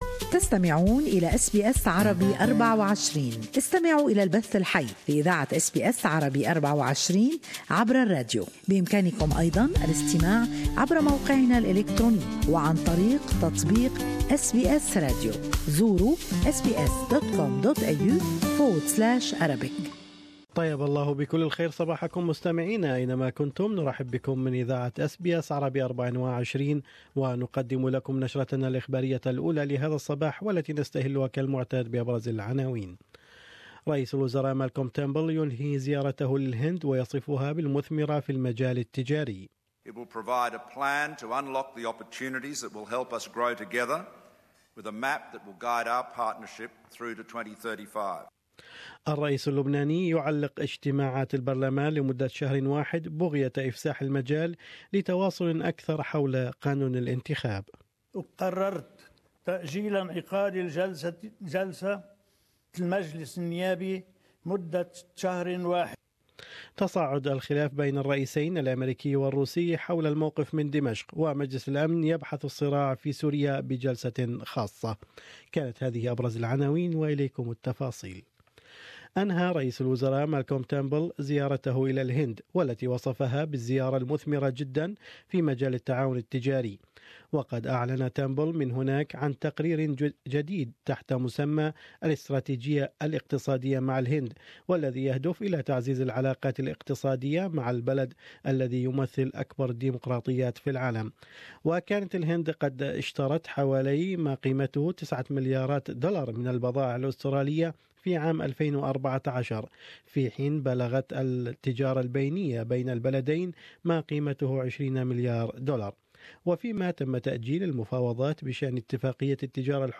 News Bulletin: Barnaby Joyce throws his support behind the Adani loan